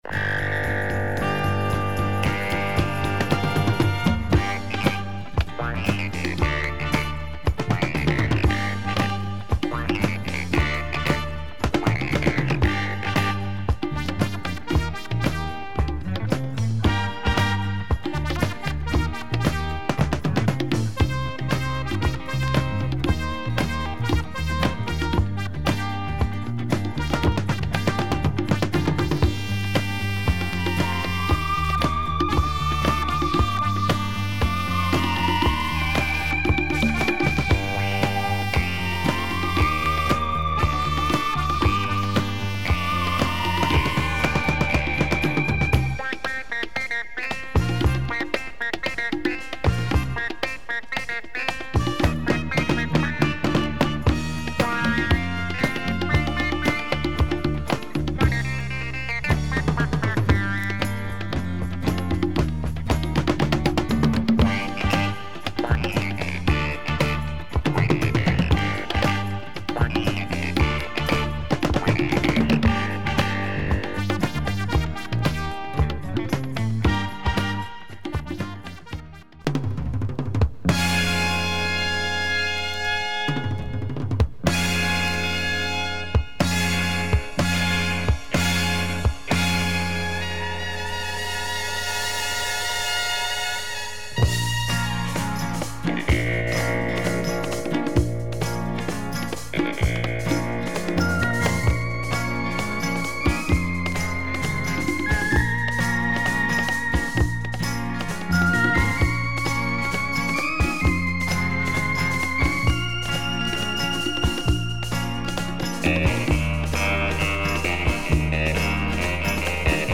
Mid 70's Asian funk